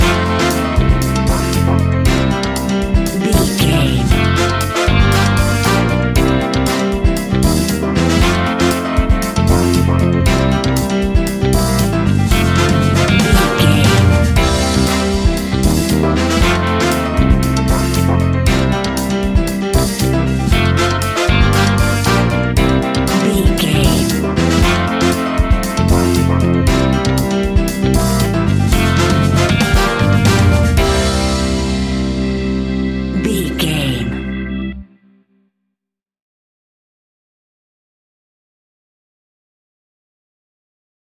Ionian/Major
flamenco
romantic
maracas
percussion spanish guitar
latin guitar